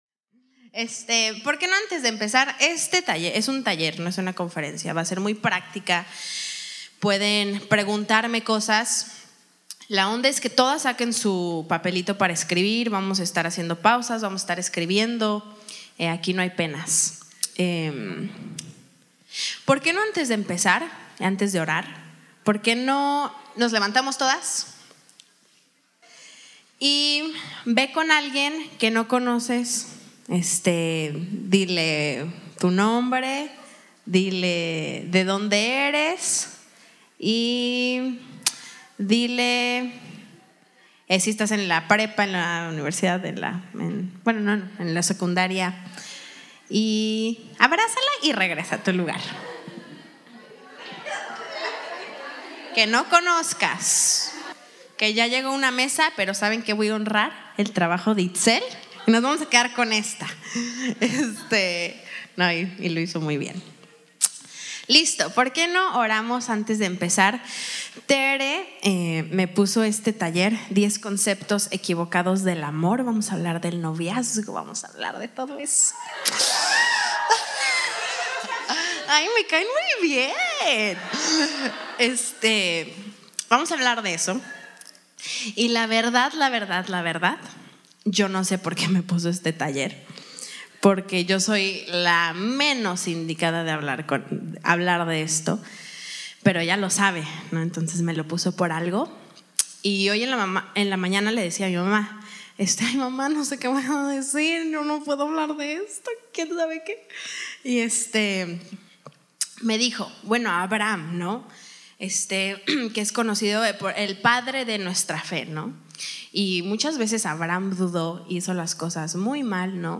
Taller – 10 Conceptos Equivocados del Amor
Retiro de Jovencitas